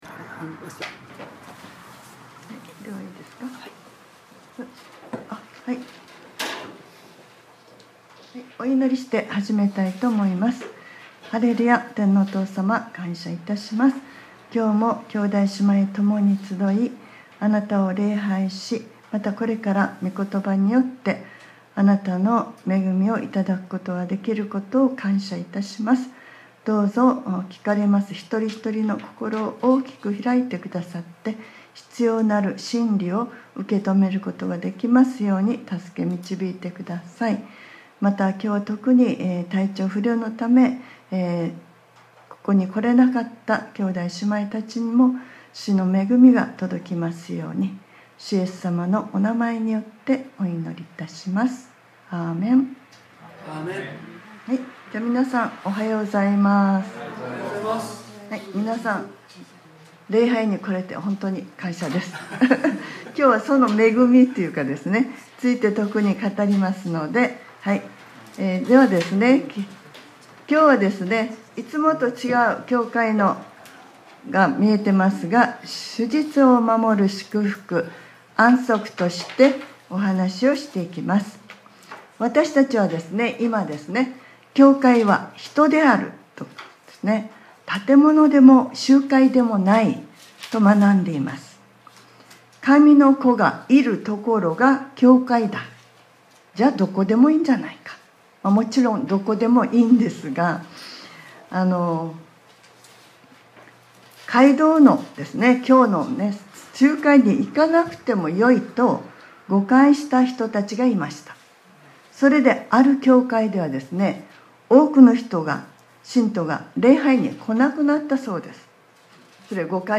2025年02月16日（日）礼拝説教『 安息日の安息 』 | クライストチャーチ久留米教会
2025年02月16日（日）礼拝説教『 安息日の安息 』